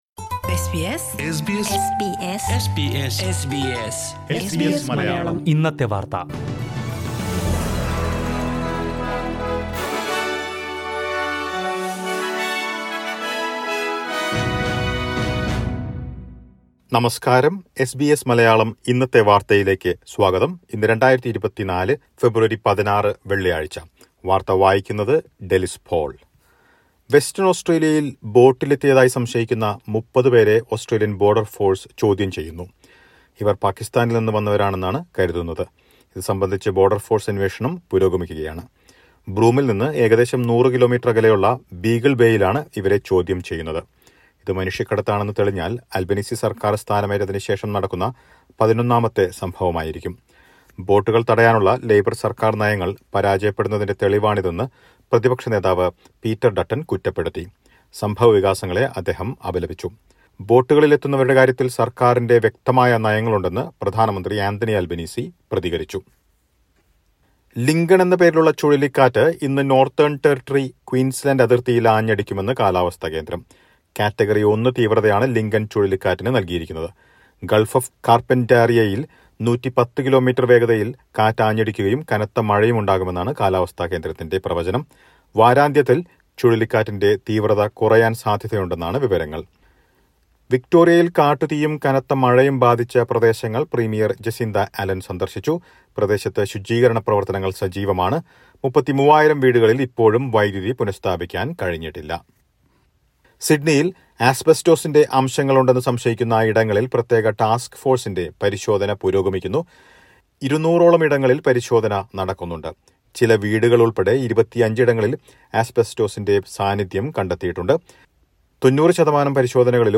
2024 ഫെബ്രുവരി 16ലെ ഓസ്‌ട്രേലിയയിലെ ഏറ്റവും പ്രധാന വാര്‍ത്തകള്‍ കേള്‍ക്കാം...